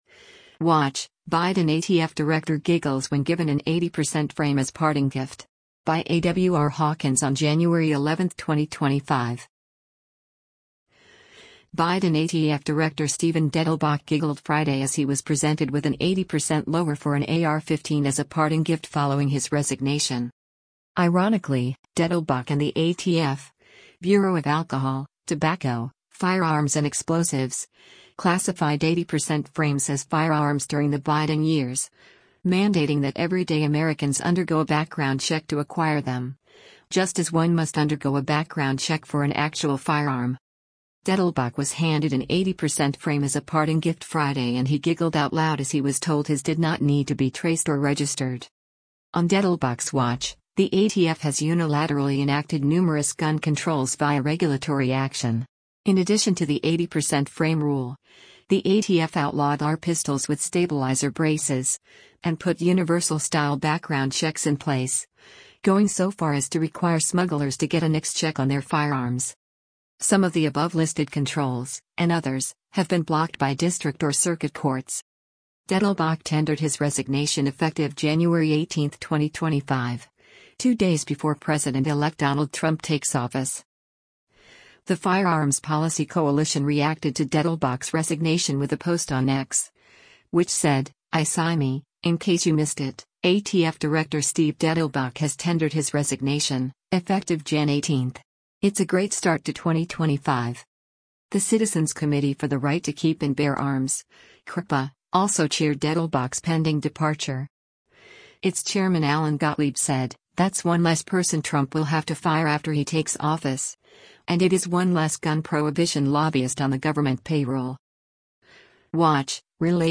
Dettelbach was handed an 80-percent frame as a parting gift Friday and he giggled out loud as he was told his did not need to be “traced” or “registered.”